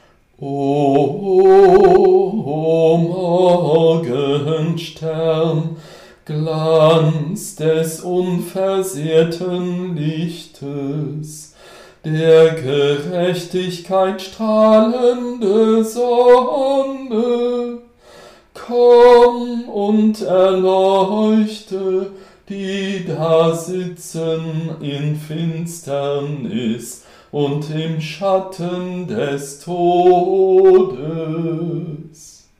Antiphon